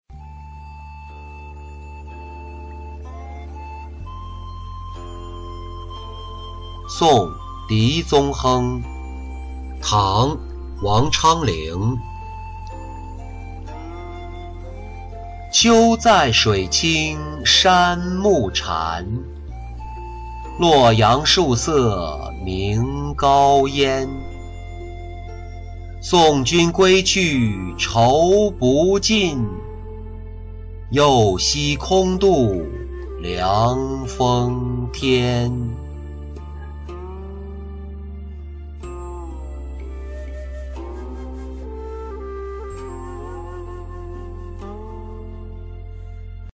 送狄宗亨-音频朗读